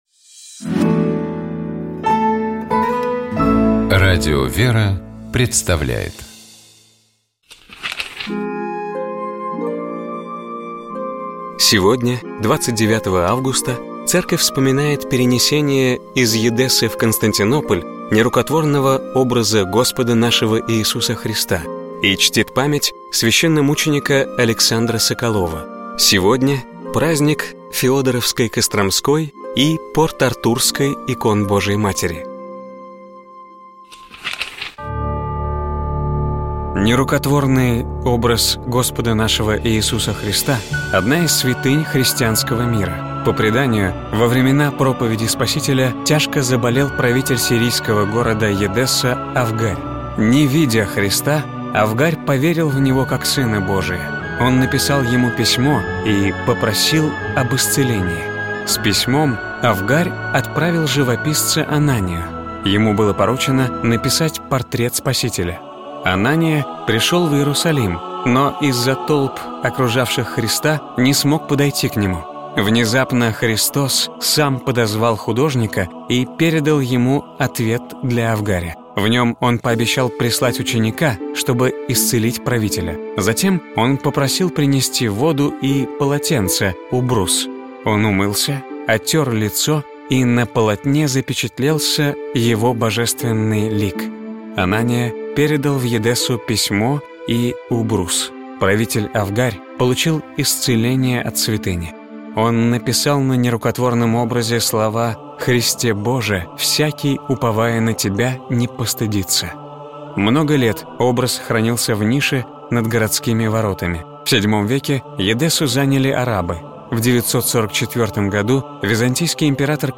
Пасхальное обращение Святейшего Патриарха Московского и всея Руси Кирилла